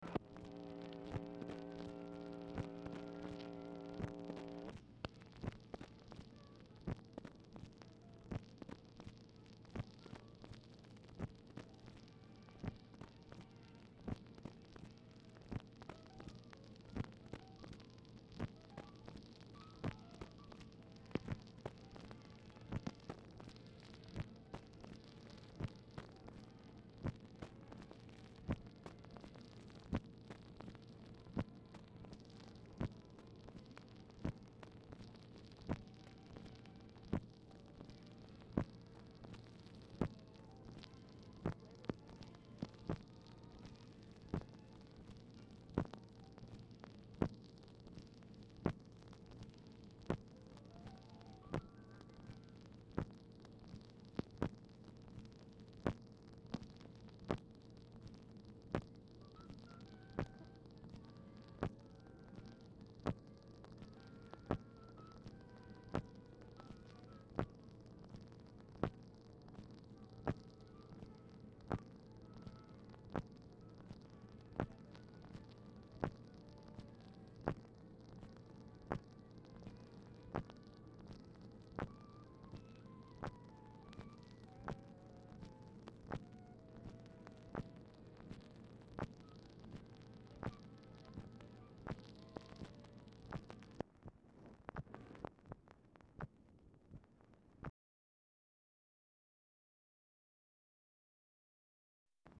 Telephone conversation # 5517, sound recording, OFFICE NOISE, 9/7/1964, time unknown | Discover LBJ
Format Dictation belt
Location Of Speaker 1 Oval Office or unknown location